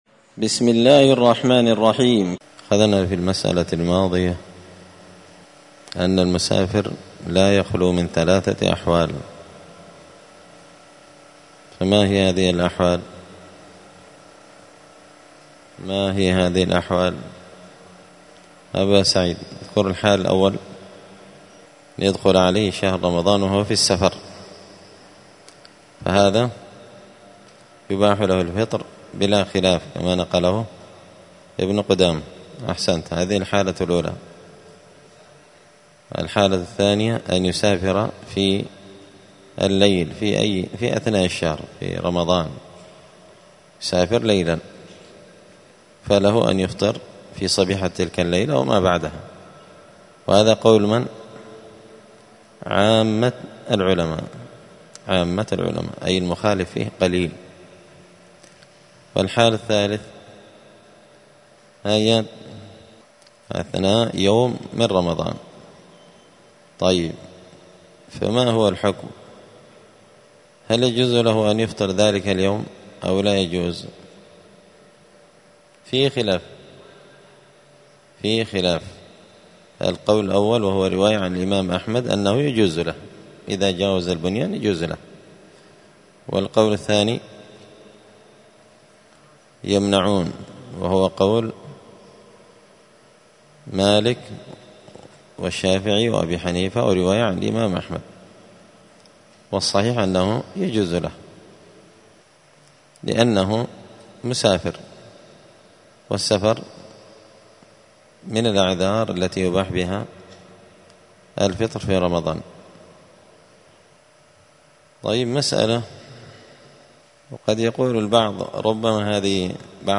دار الحديث السلفية بمسجد الفرقان بقشن المهرة اليمن
*الدرس التاسع عشر (19) {حكم الصيام لمن دخل عليه رمضان وهو مسافر…}*